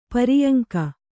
♪ pariyaŋka